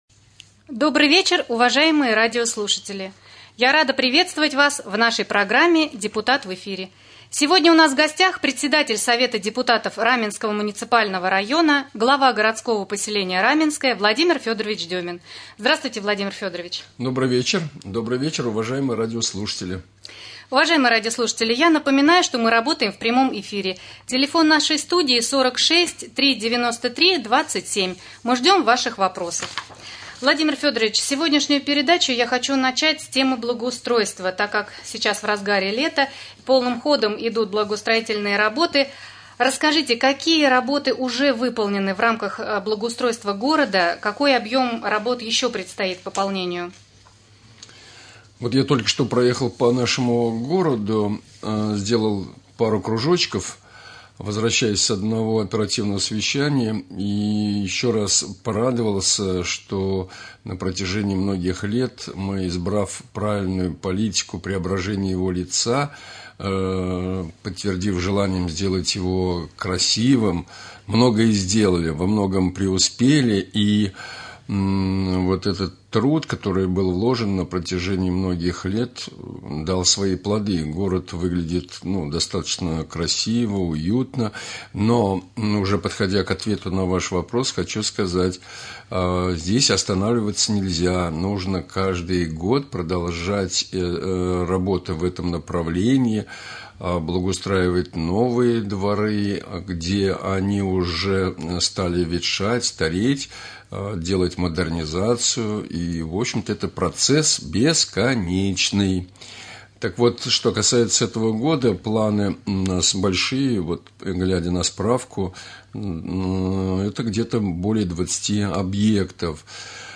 2.Прямой эфир с главой города Раменское В.Ф.Деминым.